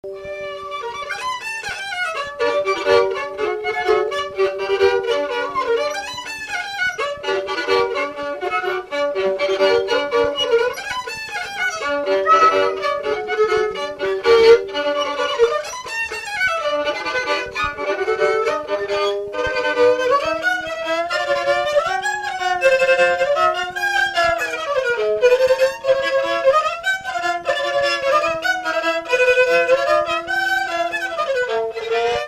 Polka
Résumé Instrumental
danse : polka
Pièce musicale inédite